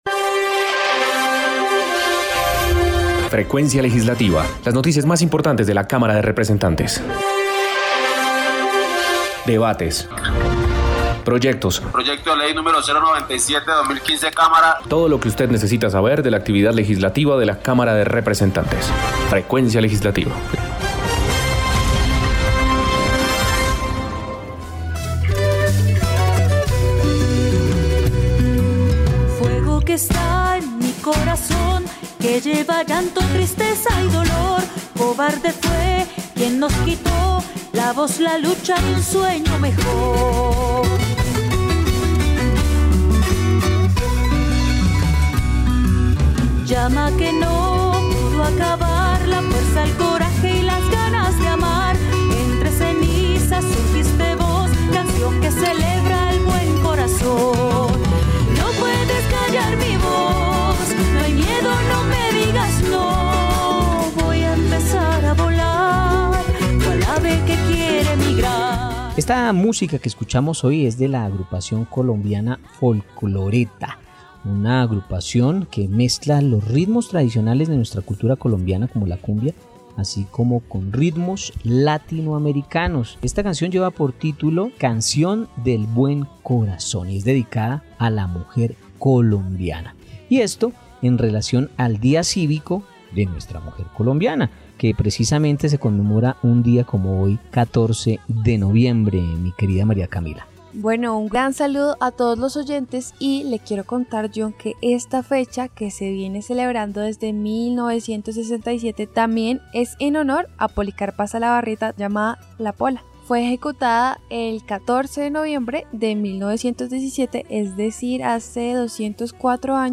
Programa Radial Frecuencia Legislativa. Domingo 14 de Noviembre de 2021